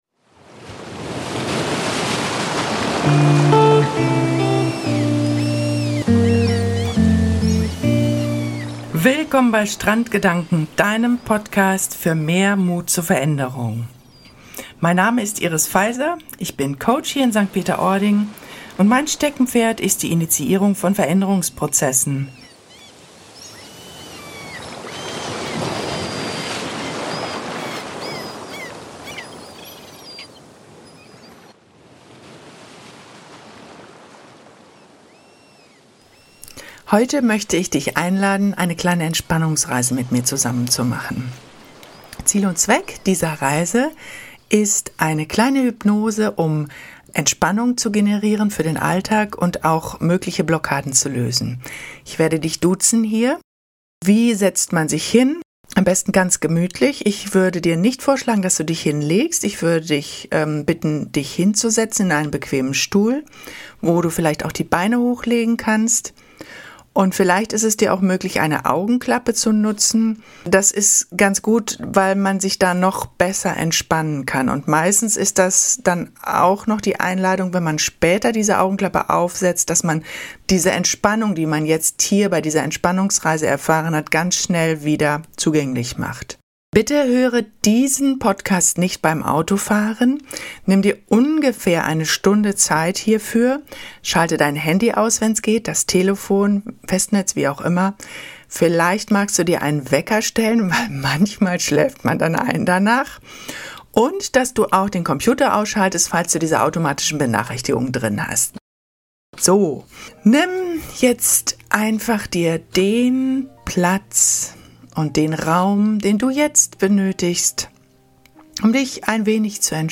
episode-10-a-entspanne-final-musik_tc.mp3